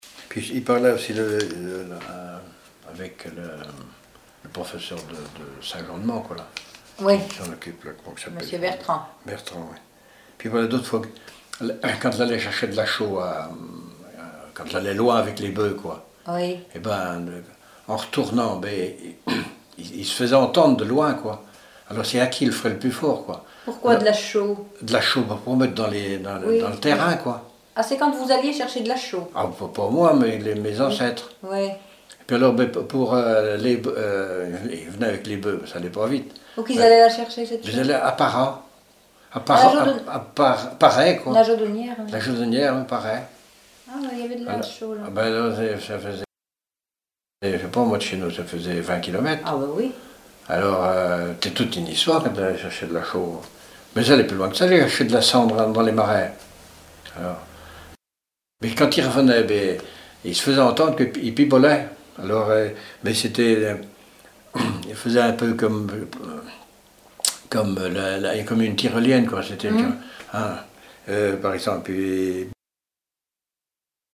chanteur(s), chant, chanson, chansonnette
Répertoire de chants brefs pour la danse
Catégorie Témoignage